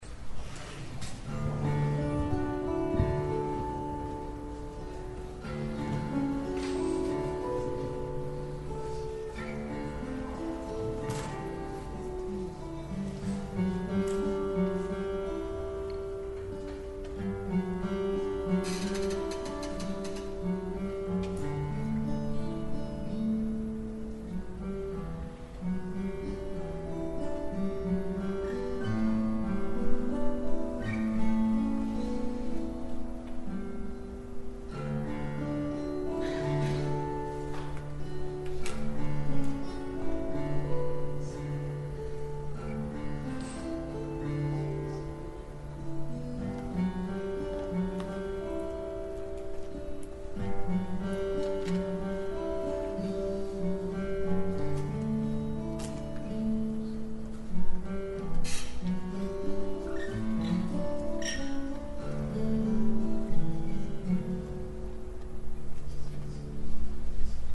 These are audio clips from the 2011 convention workshop.
Identical Lutz-topped guitars with different back and sides: